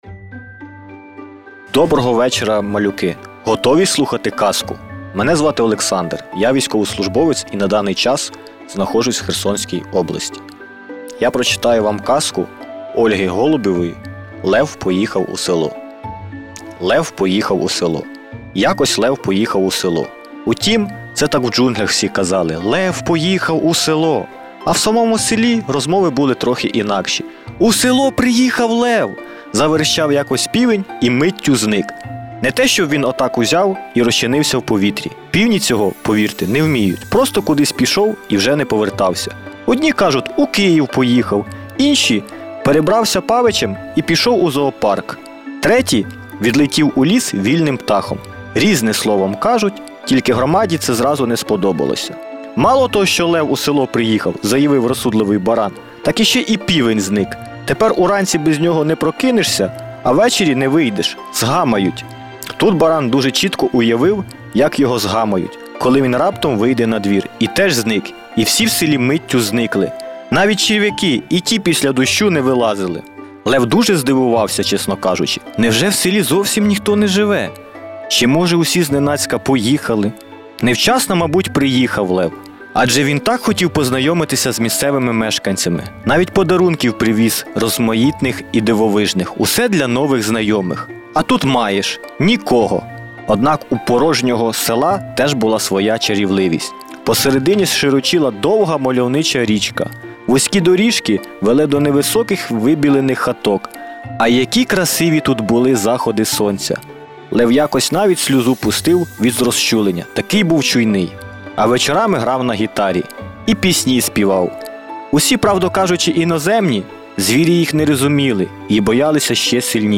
Home - Events - Казка від тата - «Лев поїхав у село» (Ольга Голубєва)
Історії від сучасних українських авторів зачитають батьки, які попри будь-яку відстань завжди поруч.